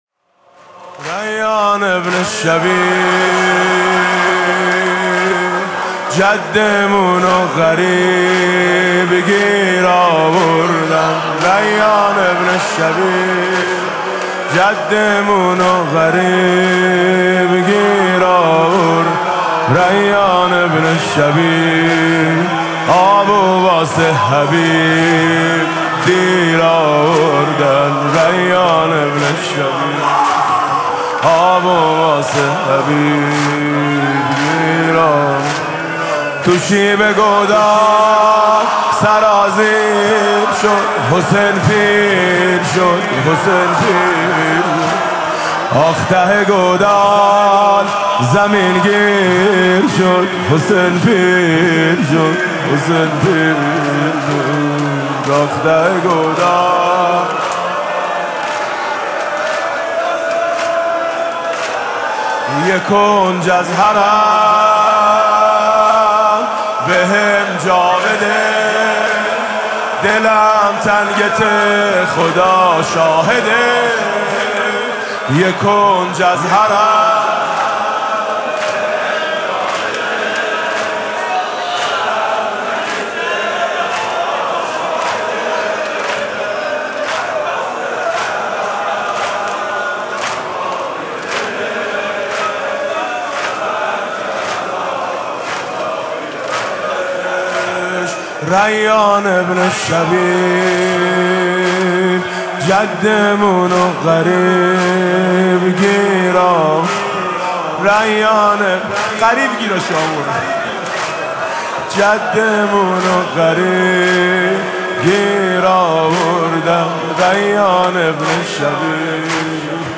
مقتل خوانی عصر عاشورا ۱۴۰۳
با نوای: حاج میثم مطیعی
ریّان بن شبیب... (زمینه)